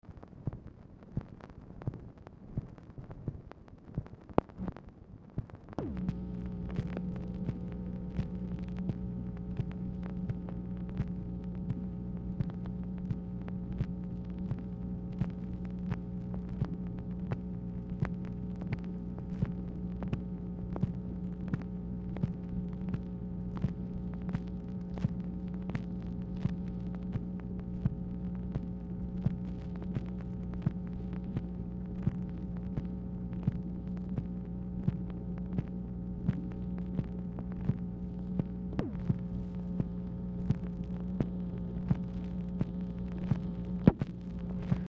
Telephone conversation # 11565, sound recording, MACHINE NOISE, 2/27/1967, time unknown | Discover LBJ
Format Dictation belt